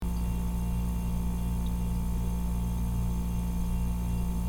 refrigerator1.mp3